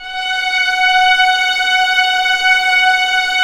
Index of /90_sSampleCDs/Roland - String Master Series/STR_Vlns 7 Orch/STR_Vls7 f slo